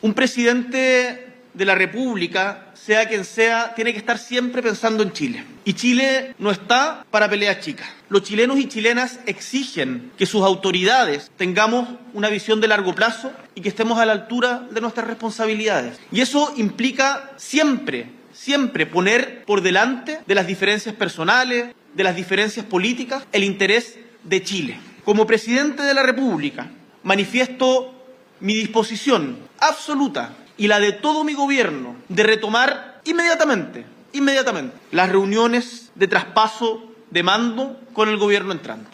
En una declaración pública realizada este miércoles 4 de marzo, el Mandatario adoptó un tono conciliador y aseguró que su administración mantiene una “disposición absoluta” para continuar con todas las reuniones bilaterales y técnicas que el equipo entrante estime necesarias.